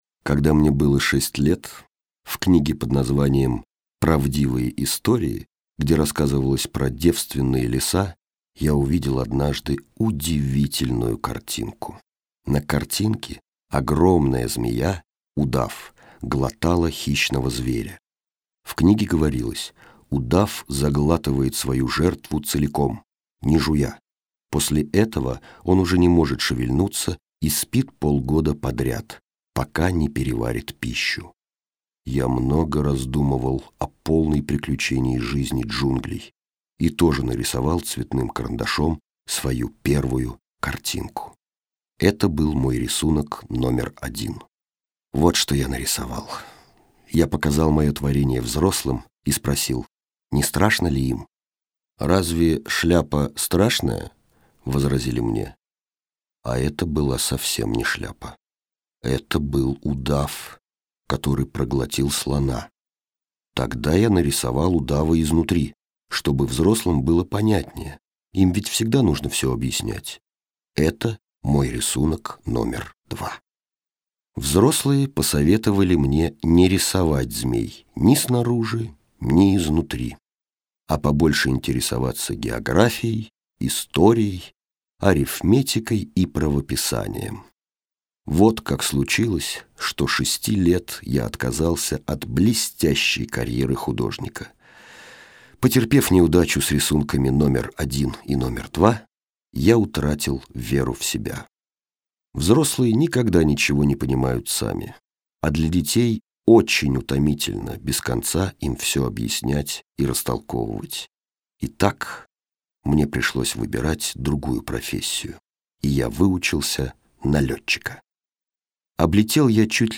Именно для этого тут К. Хабенский))), чей голос звучит в русскоязычной версии «Маленького принца».
2. на русском